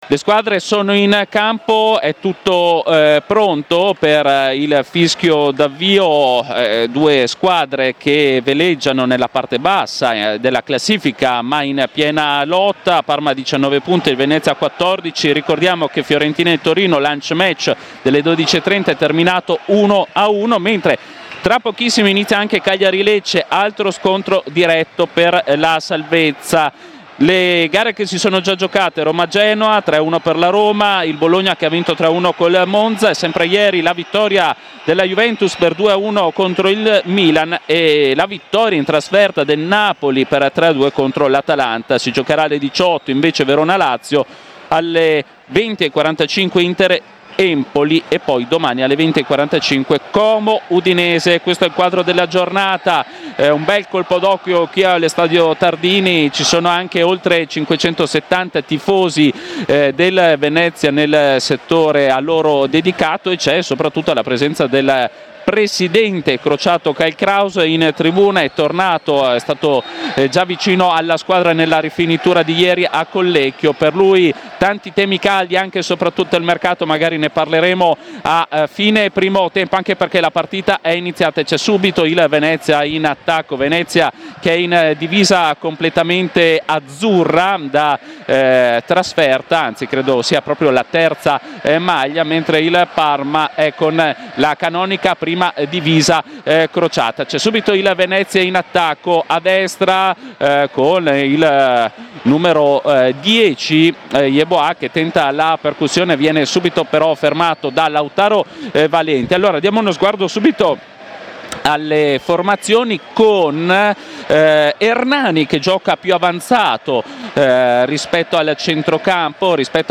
Radiocronache Parma Calcio Parma - Venezia 1° tempo - 19 gennaio 2025 Jan 19 2025 | 00:48:28 1x 00:00 / 00:48:28 Subscribe Share RSS Feed Share Link Embed